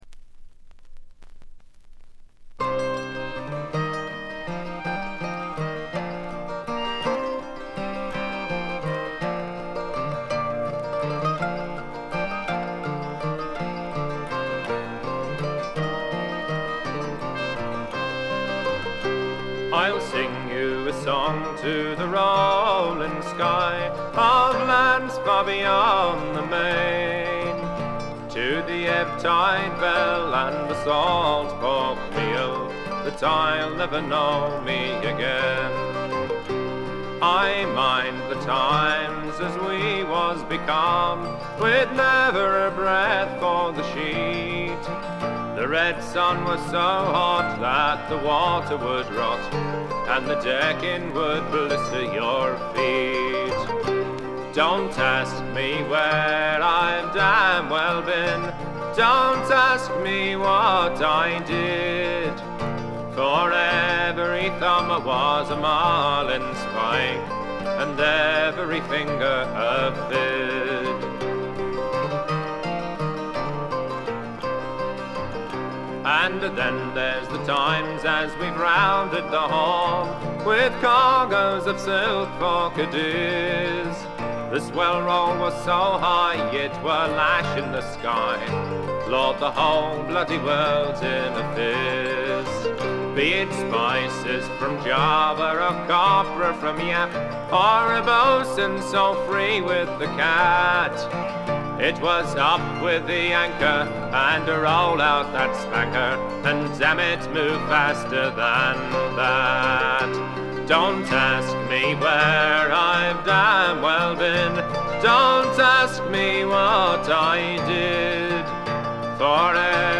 軽微なバックグラウンドノイズのみ。
試聴曲は現品からの取り込み音源です。
Mandolin